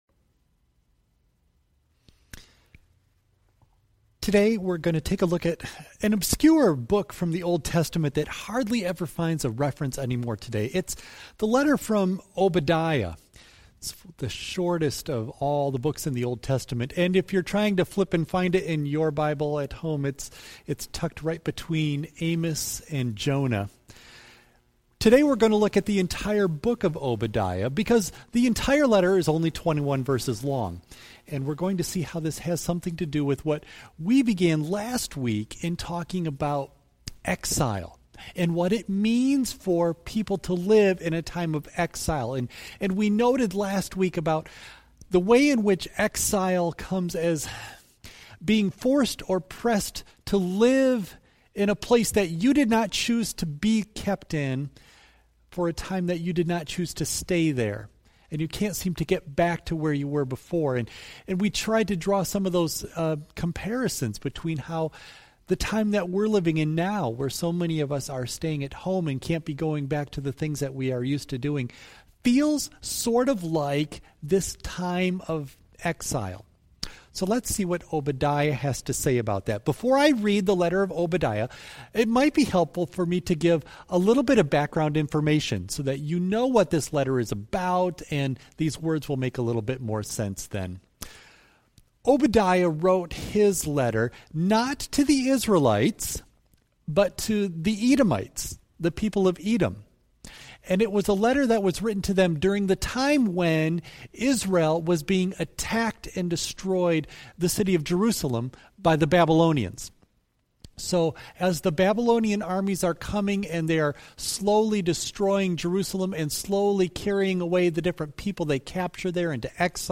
Worship Service June 14 Audio only of message